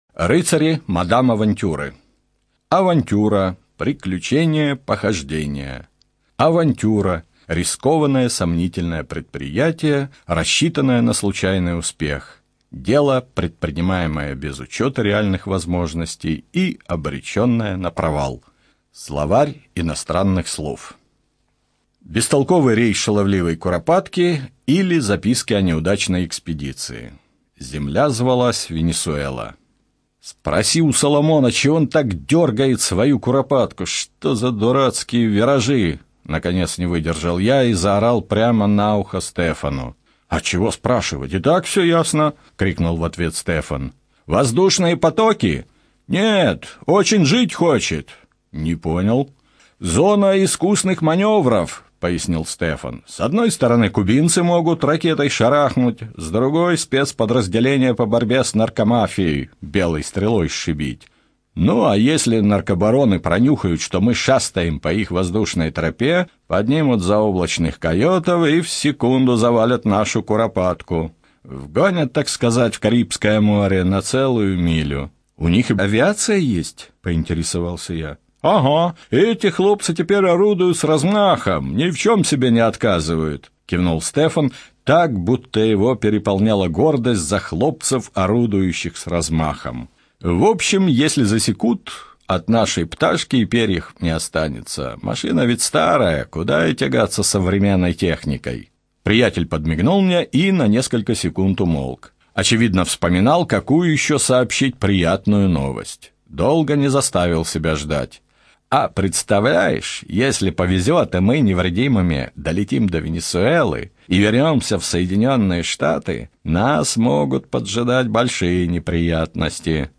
ЧитаетАвтор